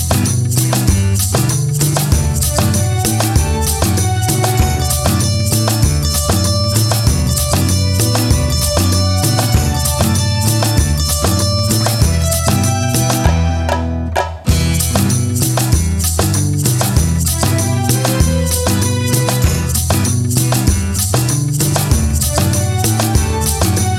no Backing Vocals Bollywood 4:39 Buy £1.50